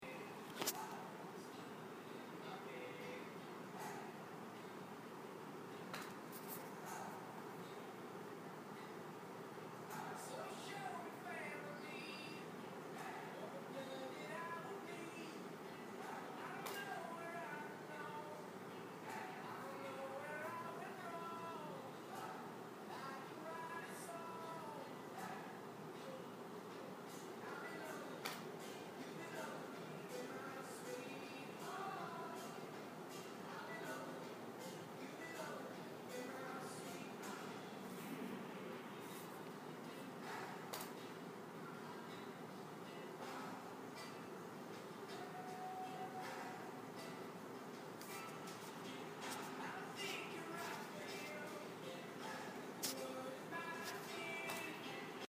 Field Recording #2